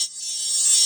Boom-Bap SFX 27.wav